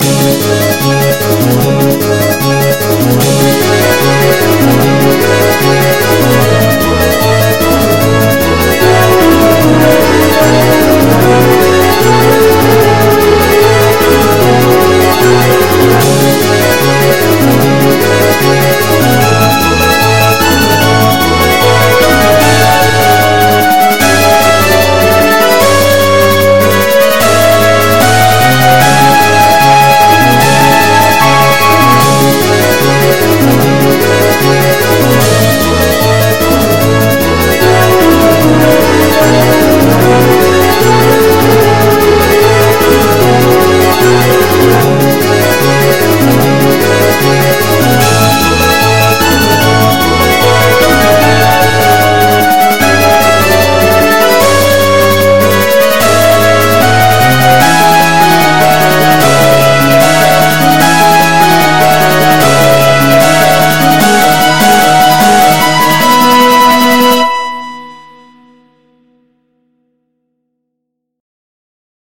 MIDI 47.72 KB MP3 (Converted) 1.1 MB MIDI-XML Sheet Music
arranged and remixed